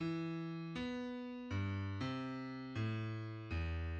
{\clef bass \tempo 4=120 e4. b8 ~ b4 g, c4. a,8 ~ a,4 e,}\midi{}